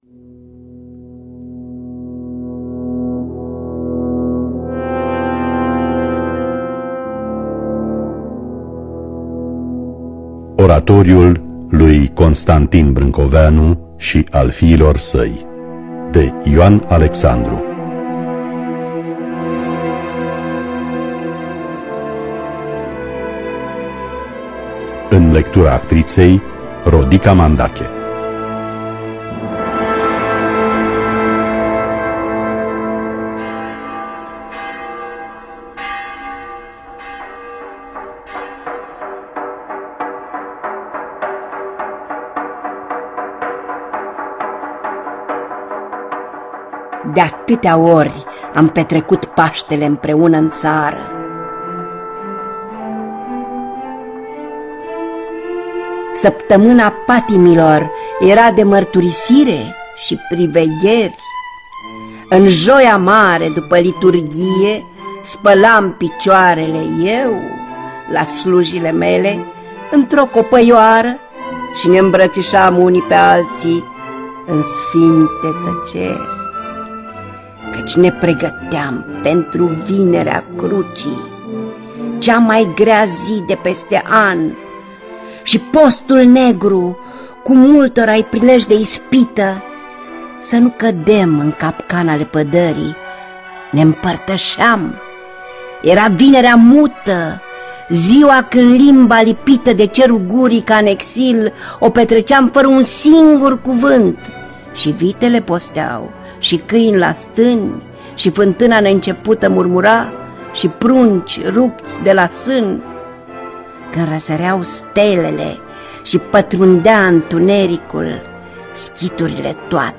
În lectura actriţei Rodica Mandache.